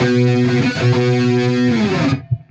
Index of /musicradar/80s-heat-samples/95bpm
AM_HeroGuitar_95-B02.wav